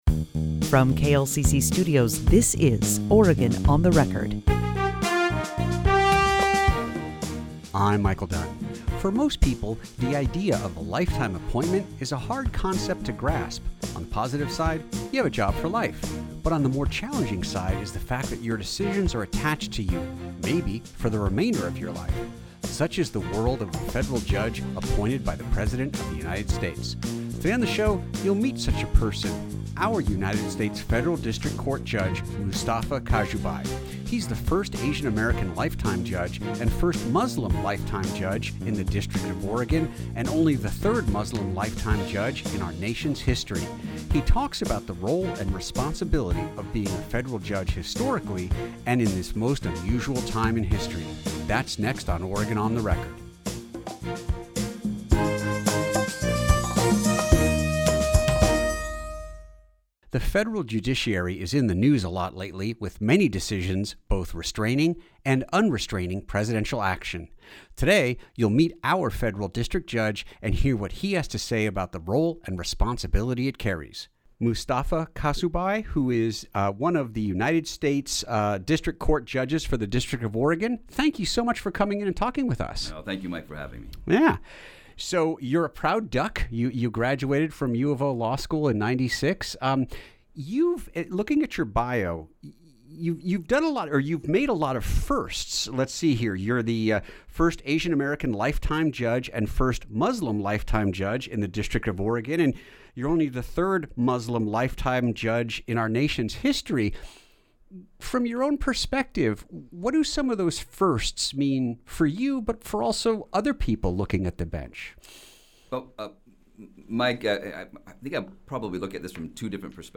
From the bench: Conversation with an Oregon judicial pioneer
Oregon On The Record From the bench: Conversation with an Oregon judicial pioneer Play episode October 13 22 mins Bookmarks Episode Description On this edition we talk with Judge Mustafa T. Kasubhai, US District Court Judge for the District of Oregon. Kasubhai is the first Asian American lifetime judge and first Muslim lifetime judge in the District of Oregon.